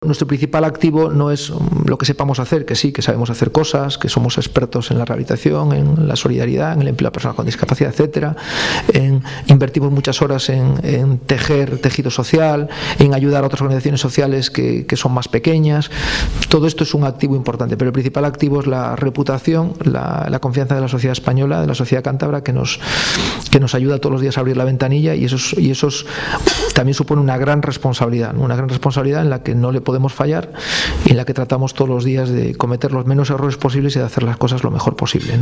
Ante una audiencia que llenó a rebosar el Salón Sardinero del Hotel Real de Santander